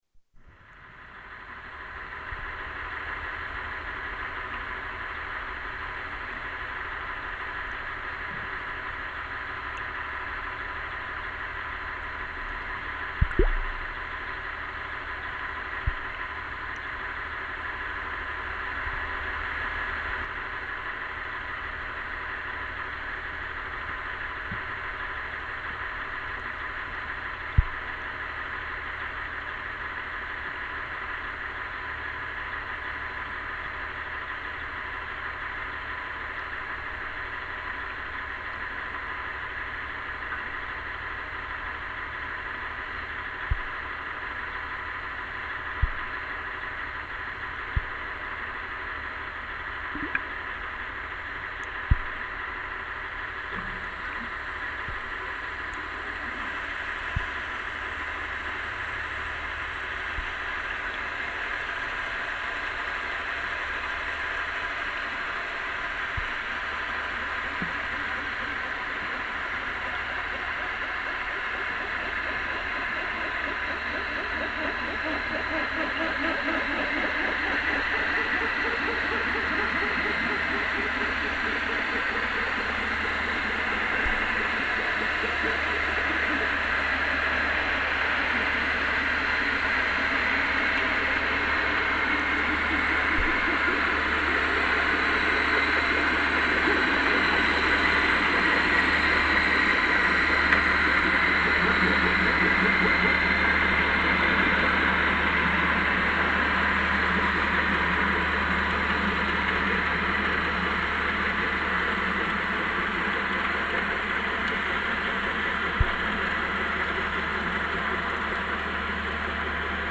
Underwater sounds of a ferry